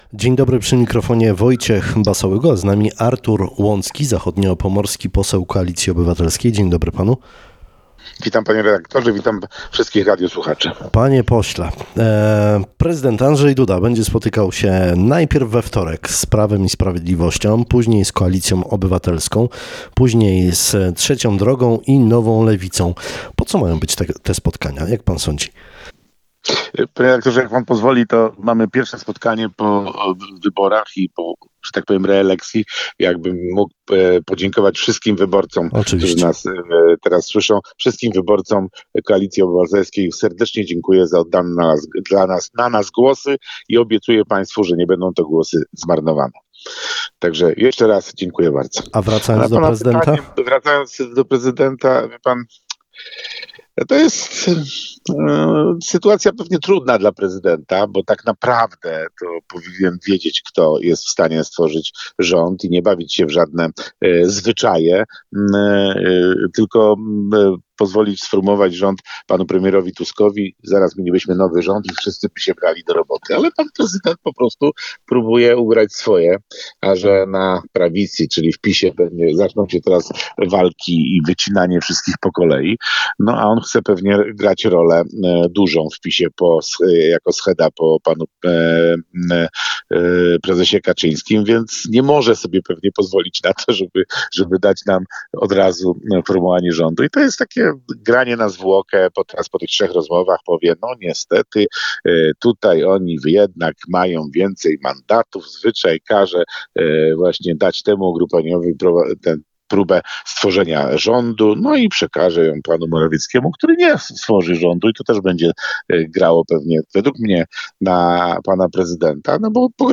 Jeśli nowy rząd będzie złożony z polityków Koalicji Obywatelskiej, poseł Artur Łącki zapowiada kontynuację inwestycji po poprzednikach na Pomorzu Zachodnim. Tak dziś rano mówił w Rozmowie Dnia.